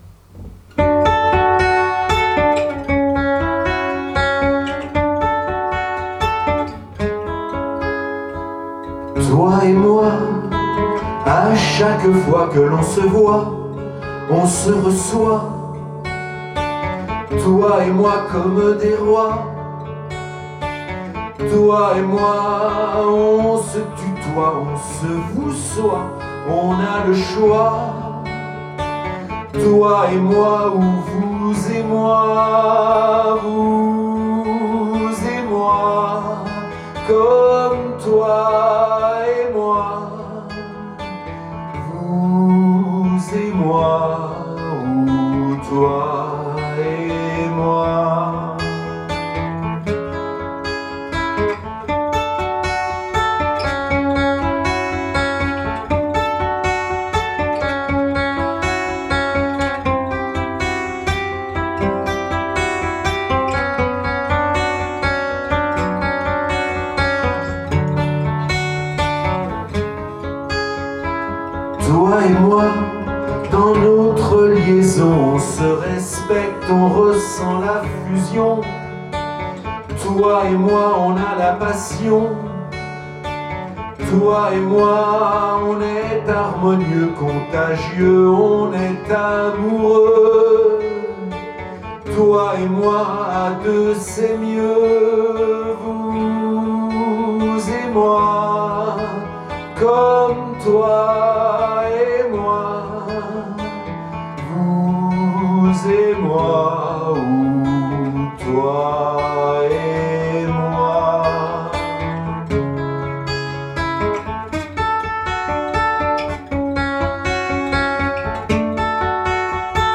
Rumba, slow, la fusion d’un couple harmonieux.